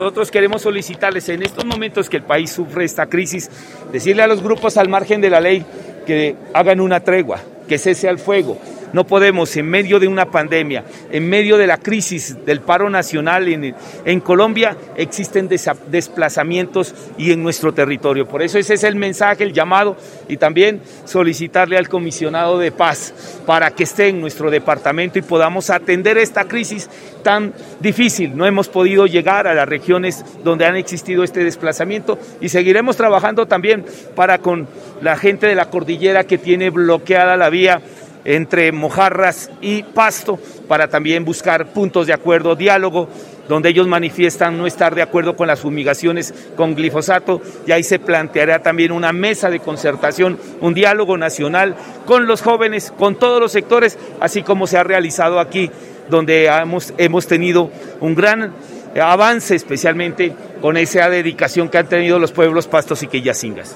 Declaración del Gobernador de Nariño Jhon Rojas Cabrera: